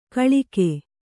♪ kaḷike